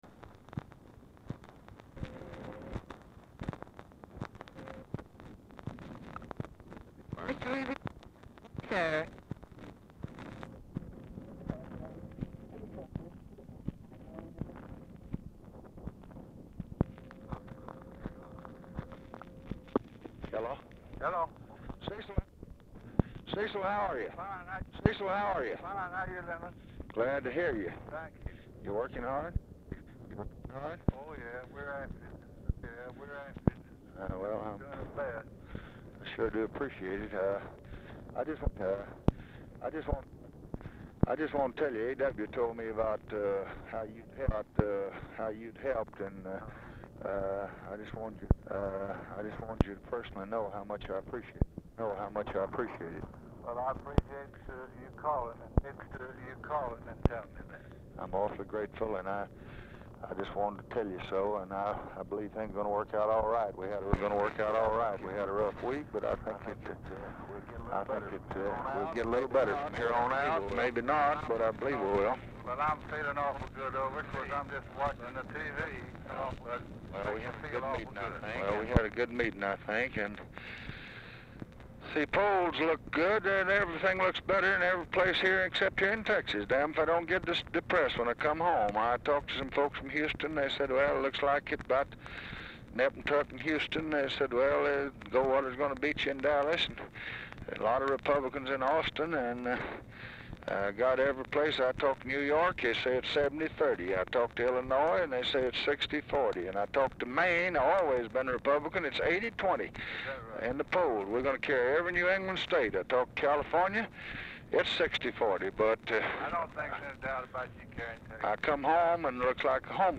POOR SOUND QUALITY
Location Of Speaker 1 LBJ Ranch, near Stonewall, Texas
Specific Item Type Telephone conversation